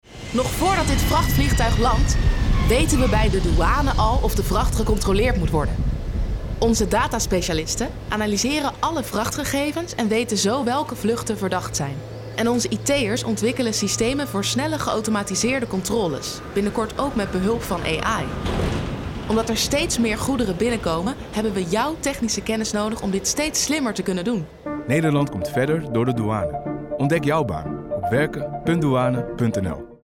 Radiospot wervingscampagne data-analisten en ICT'ers